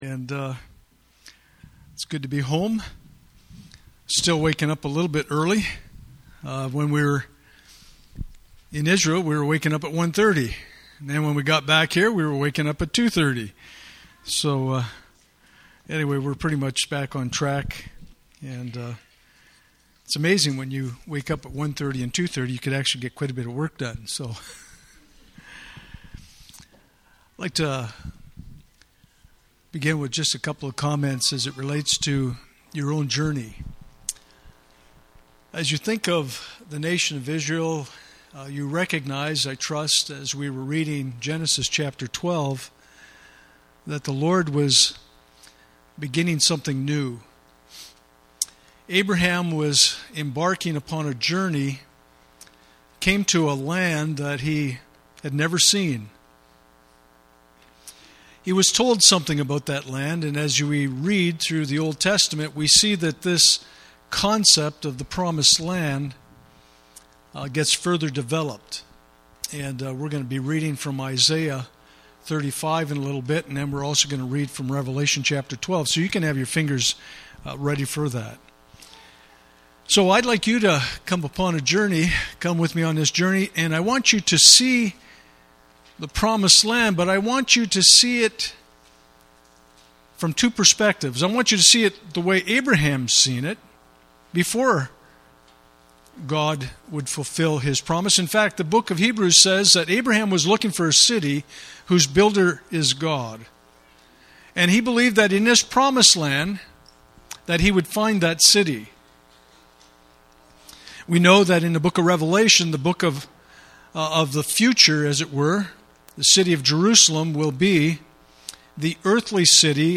Passage: Genesis 12:1-9 Service Type: Sunday Morning